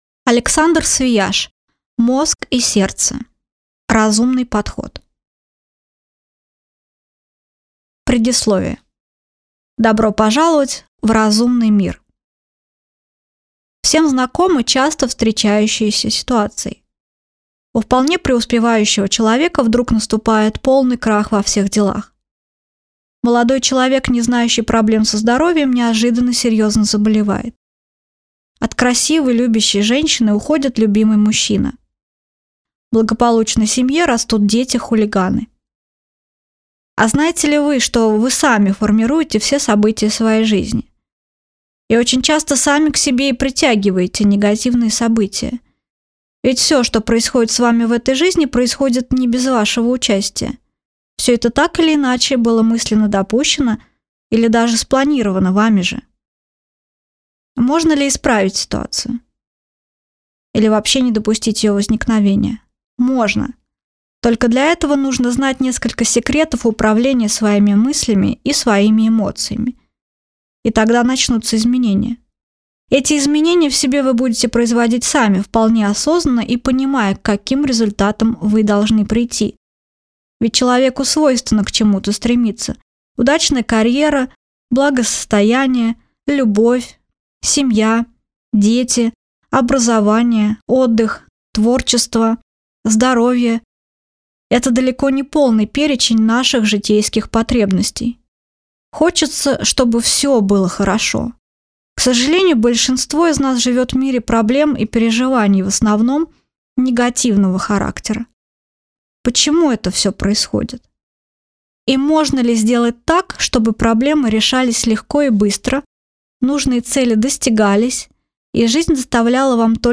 Аудиокнига Мозг и сердце. Разумный подход | Библиотека аудиокниг